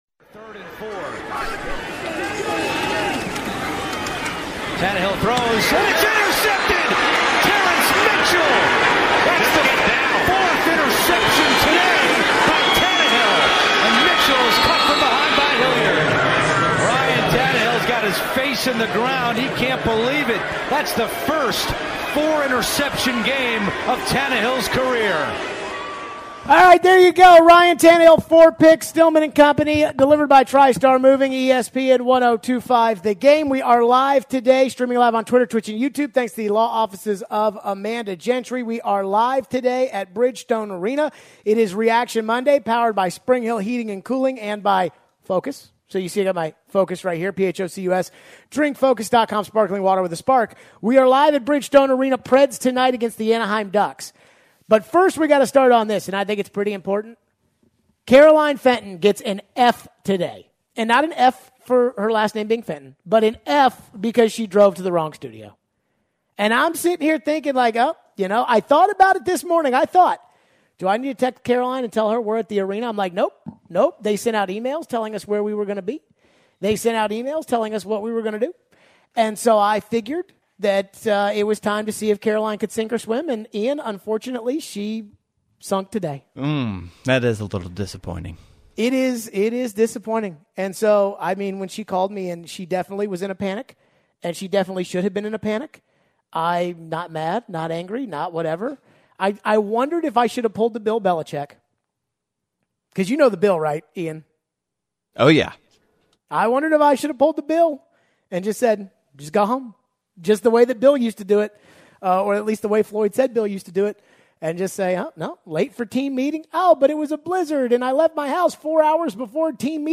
We take your phones as always.